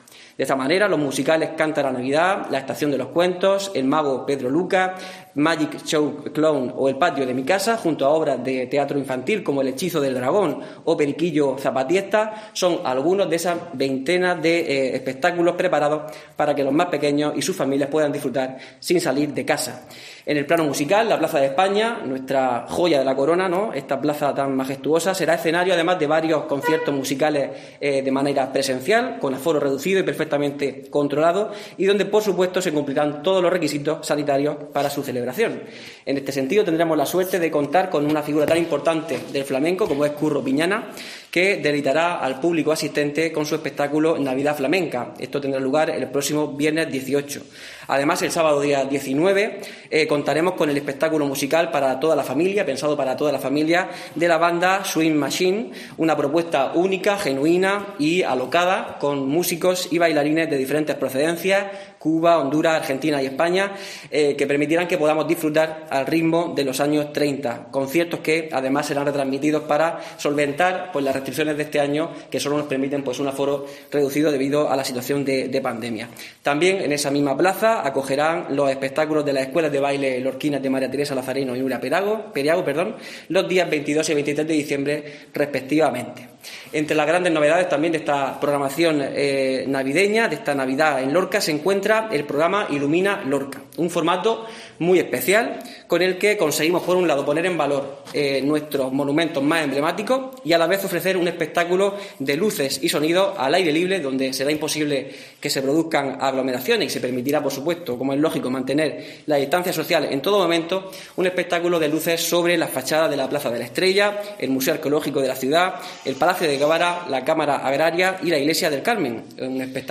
José Ángel Ponce, edil de festejos del Ayuntamiento de Lorca